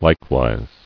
[like·wise]